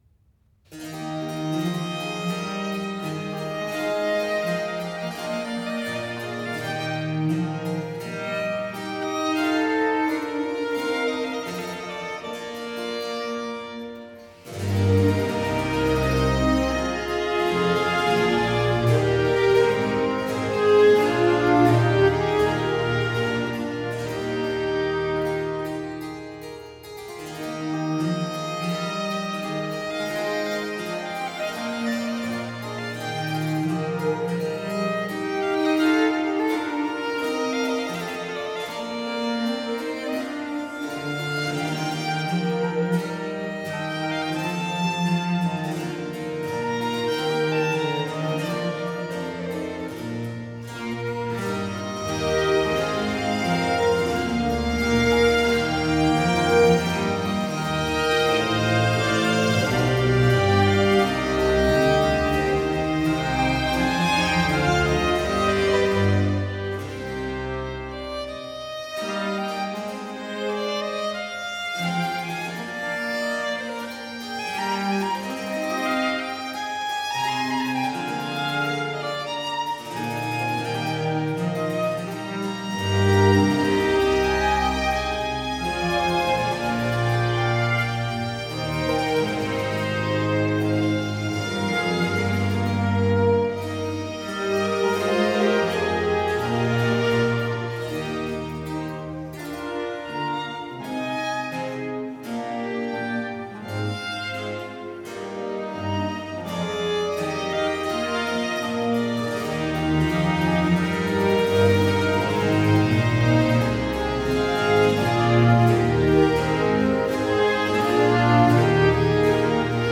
Giga, vivace